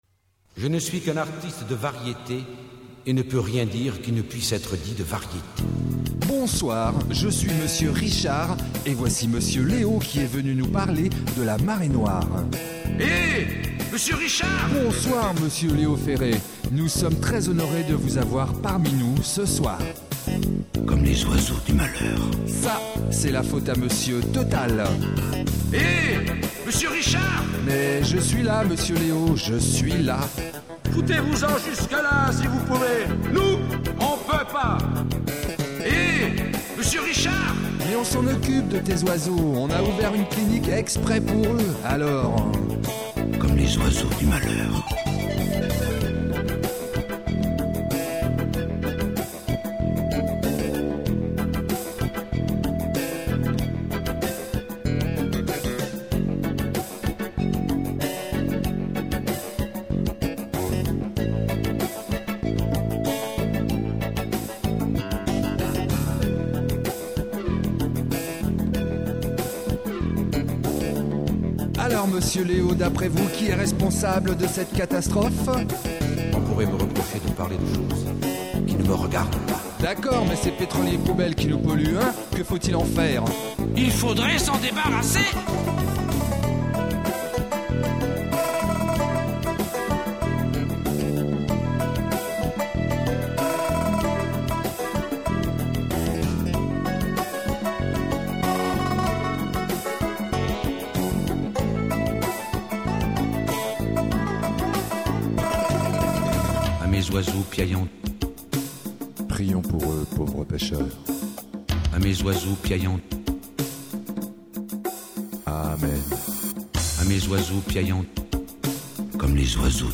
Le m�me en format mp3 (St�r�o et tout et tout !)